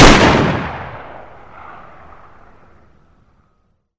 Index of /server/sound/weapons/cw_serbu_shorty
fire.wav